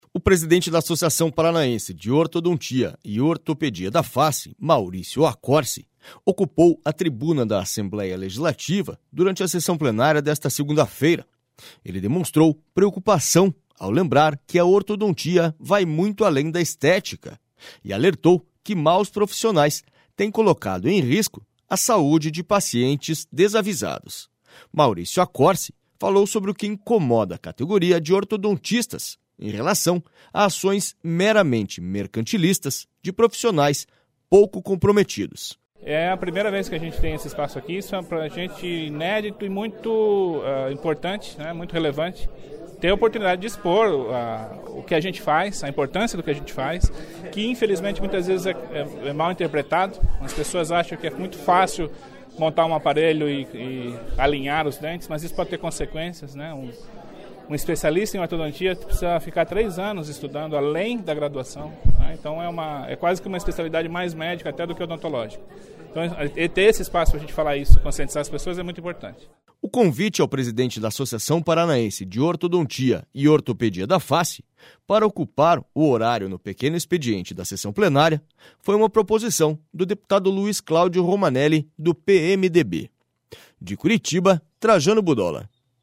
boletimALEPOrtodontia.mp3